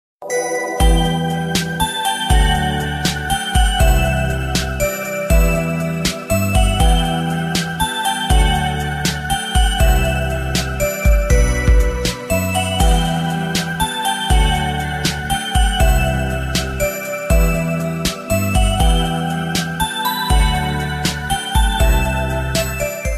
Categories IPhone Ringtones